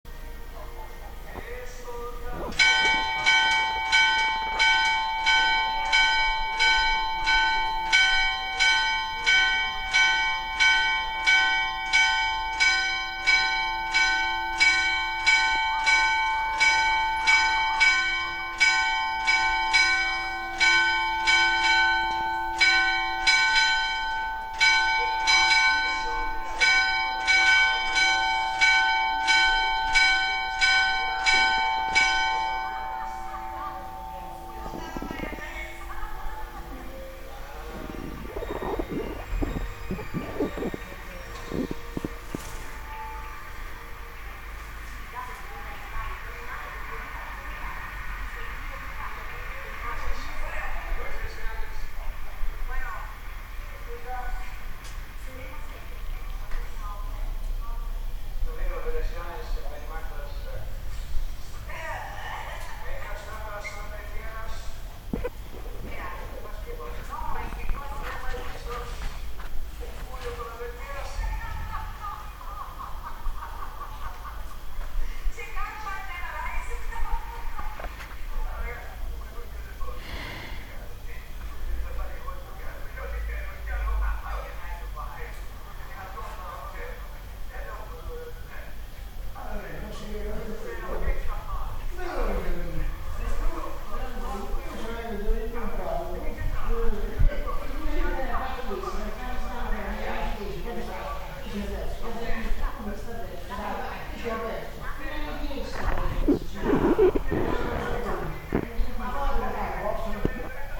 Suono-Della-Sera.mp3